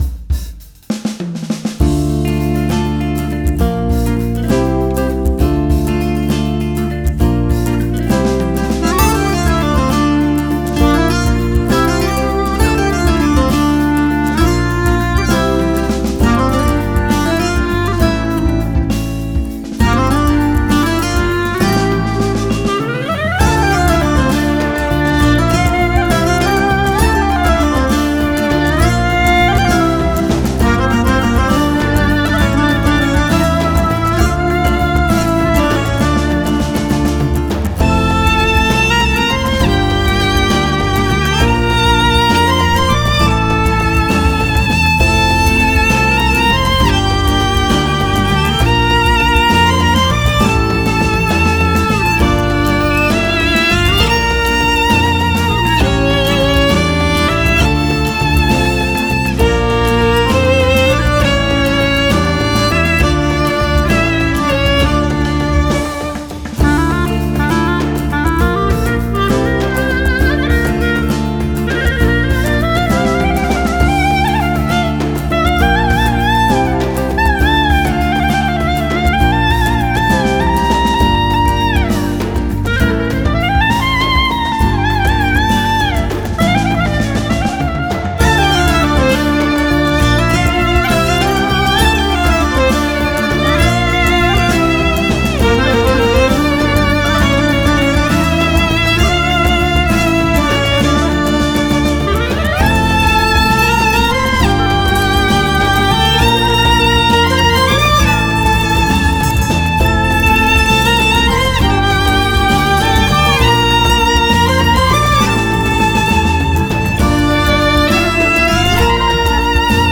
mutlu eğlenceli rahatlatıcı fon müziği.